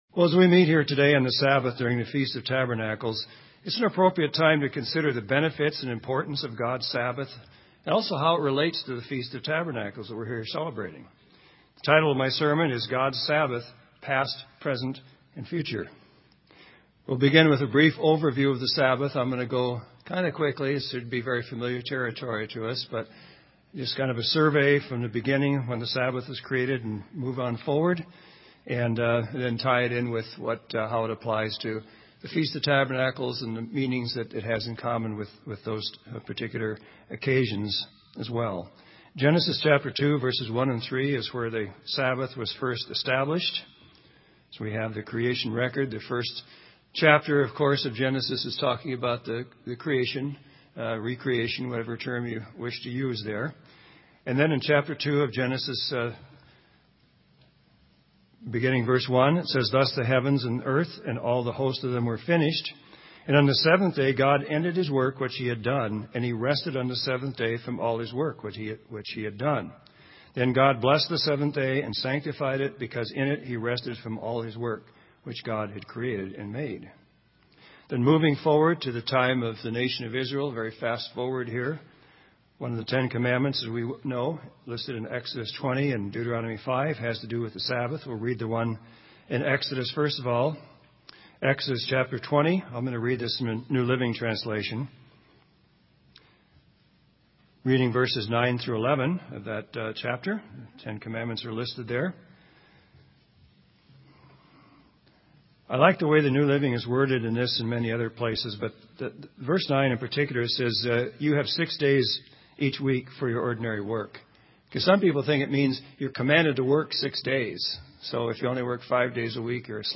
This sermon was given at the Bend, Oregon 2012 Feast site.